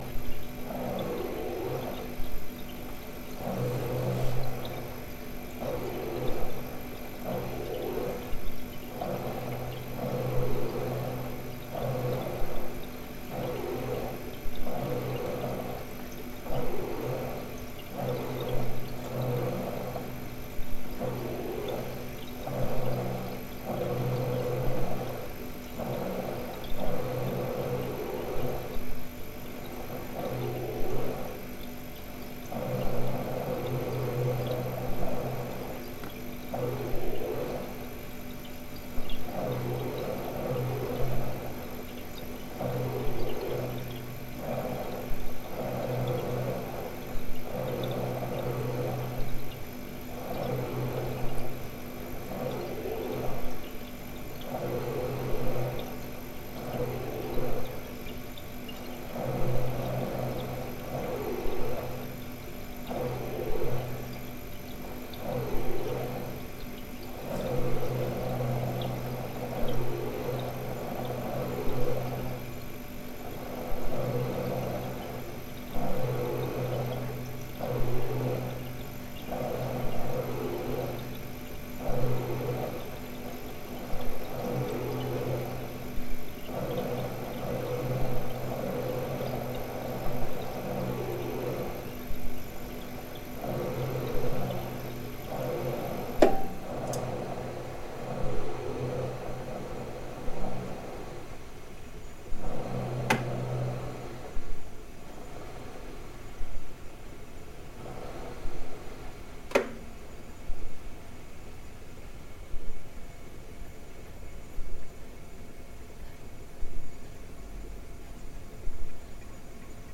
Kuehlschrank.mp3